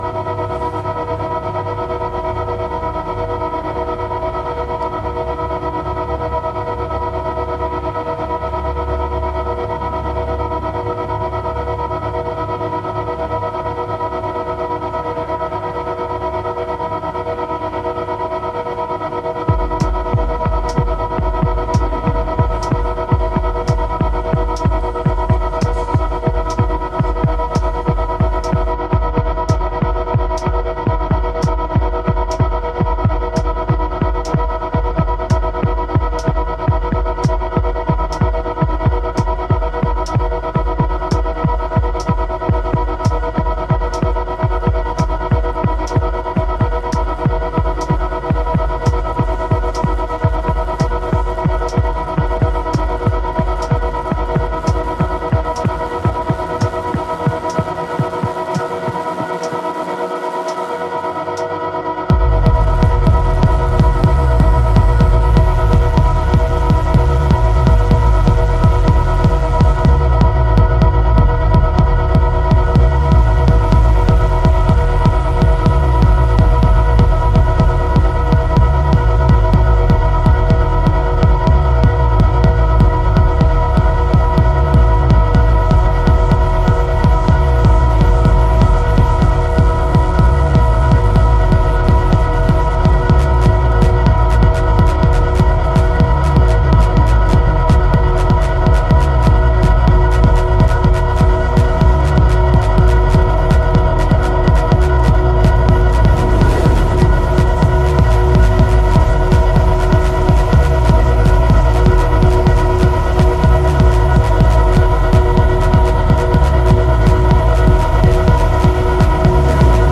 Electronix Techno